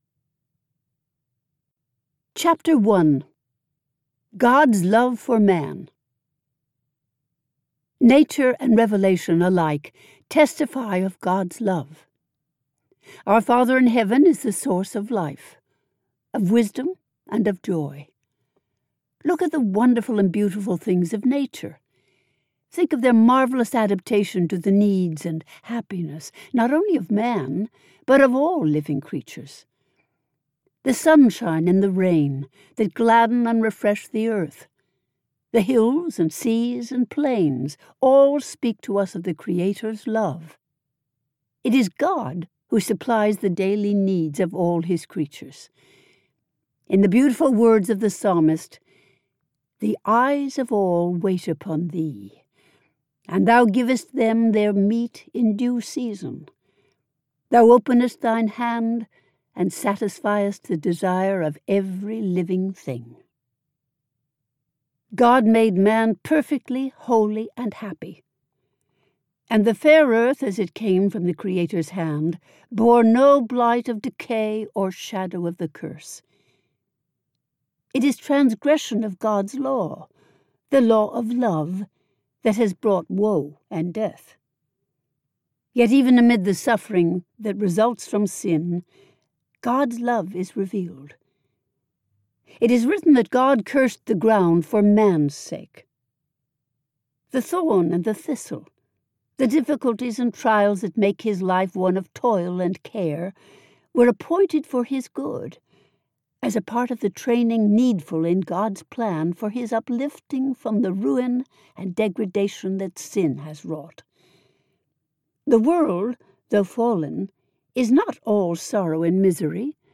In Search of Peace Audio book - MP3 Download